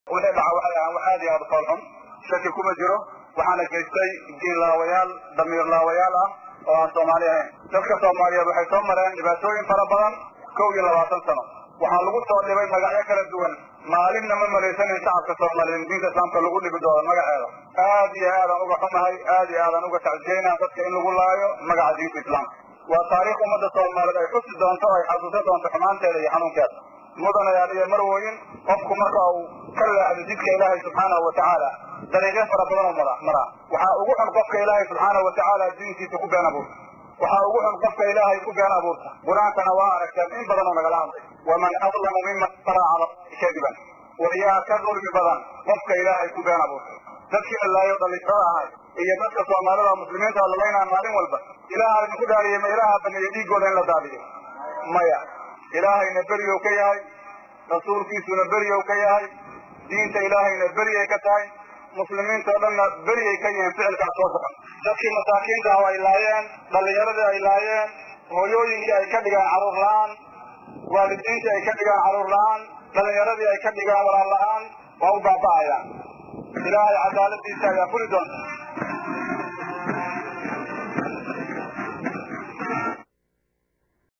Banaabax loogu magac daray maalinta cadhada ayaa lagu qabtay Garoonka burburay ee Koonis Stadium ee magaalada Muqdisho.